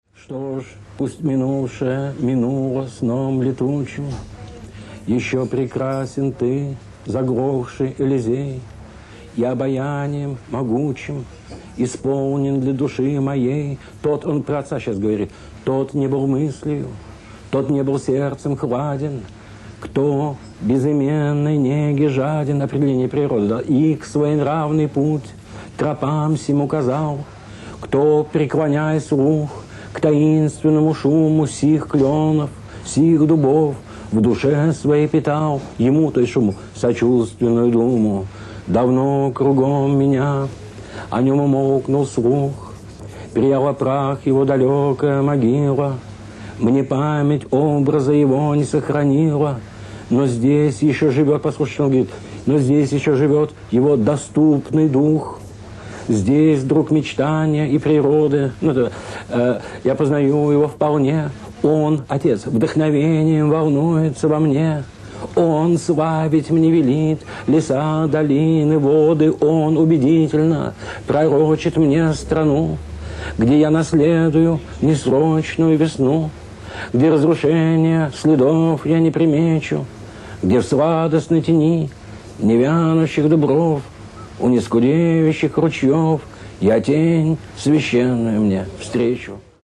1. «Евгений Баратынский – Запустение (читает и комментирует И. Бродский)» /